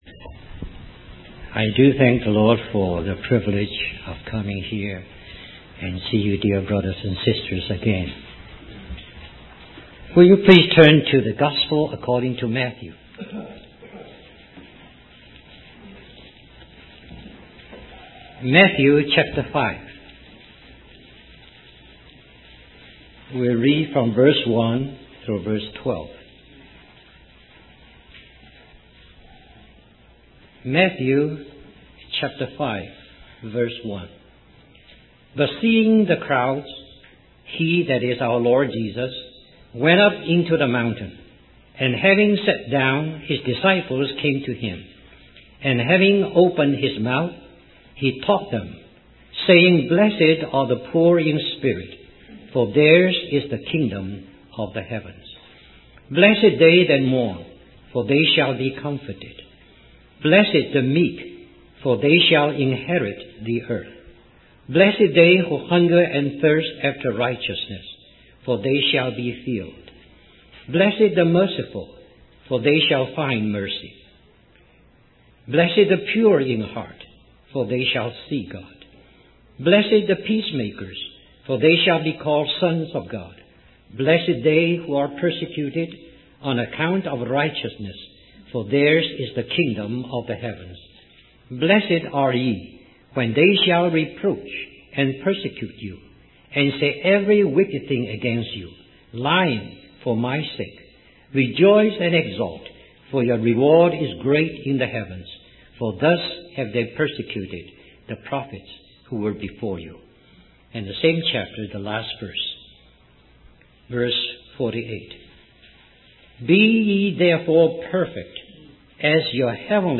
In this sermon, the preacher emphasizes the importance of surrendering oneself completely to the Lord in order to be taught by Him. He explains that Jesus showed His disciples what the kingdom of God is and how to live in it.